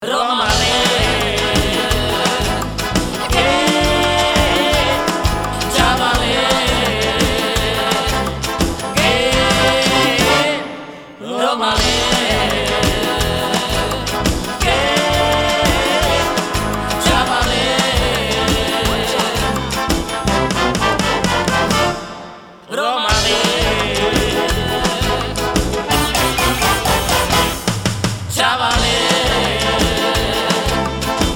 key: Am / Bbm